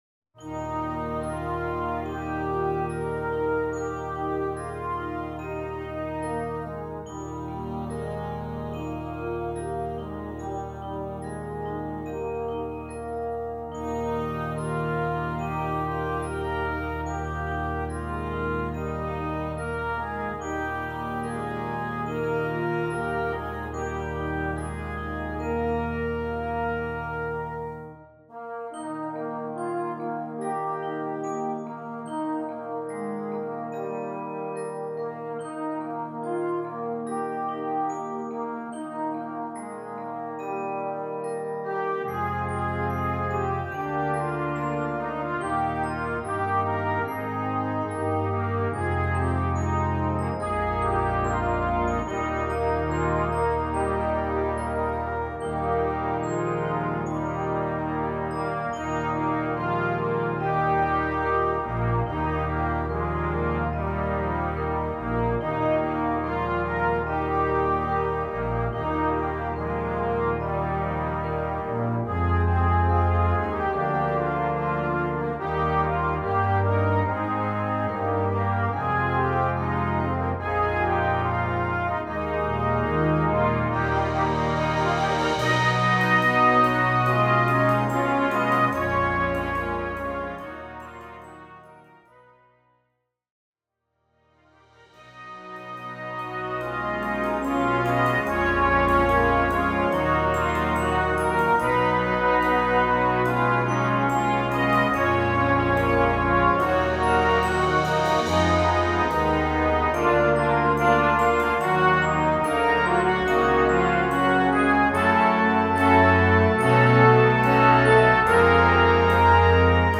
Weihnachtsmusik für Blasorchester
Besetzung: Blasorchester